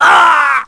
Mitra-Vox_Damage_kr_02.wav